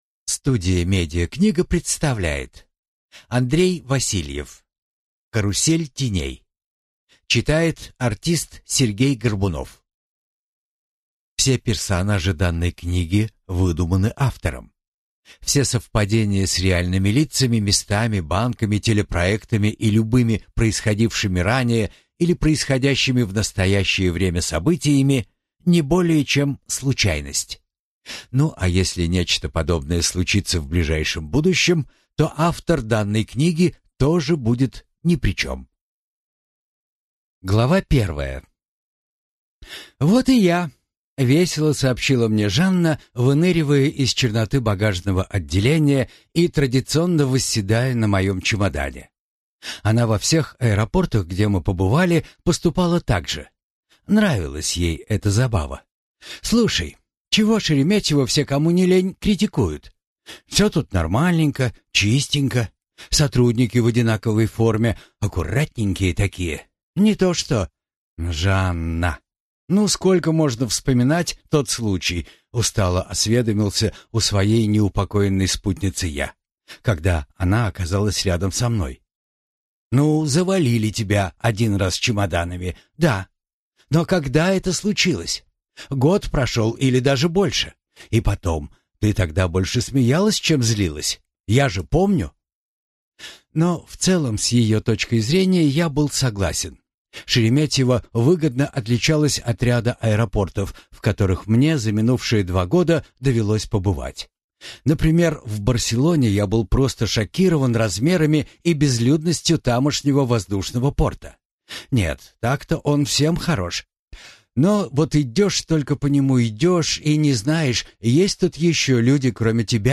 Аудиокнига Карусель теней | Библиотека аудиокниг